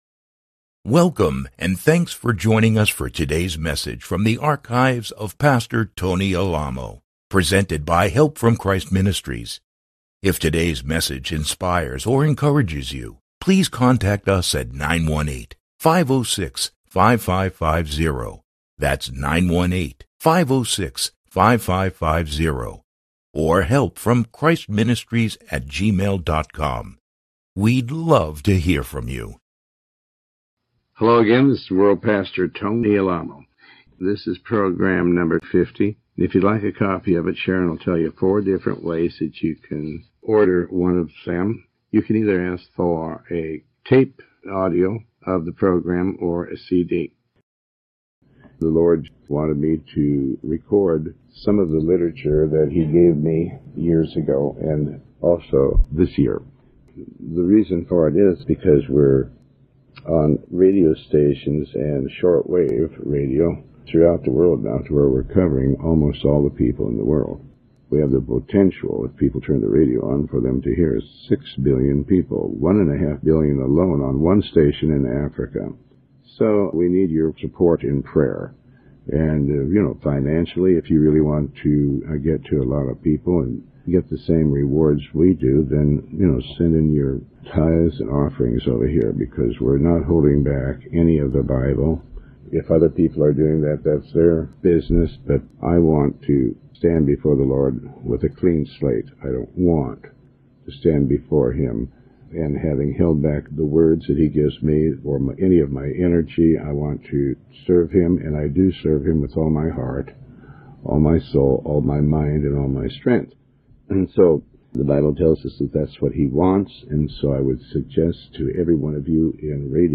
Tony Alamo Sermon 50A